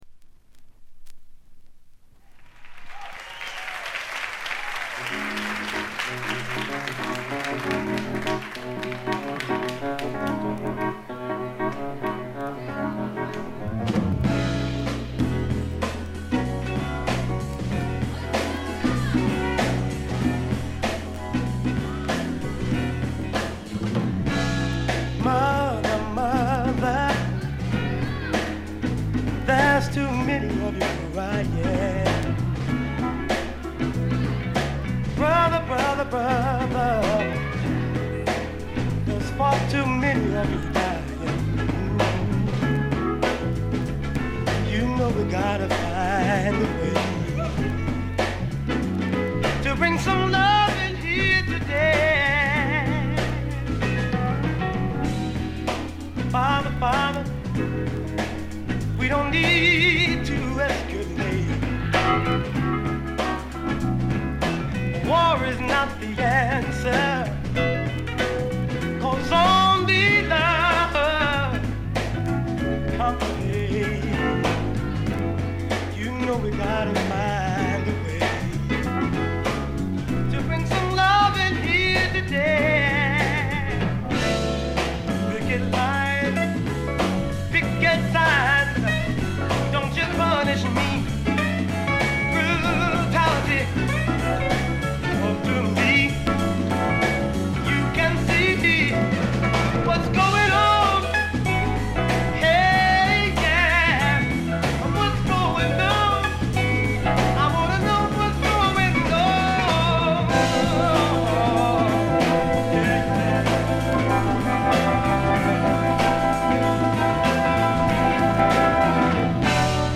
軽微なチリプチ少々（B面冒頭等）。全編良好に鑑賞できると思います。
試聴曲は現品からの取り込み音源です。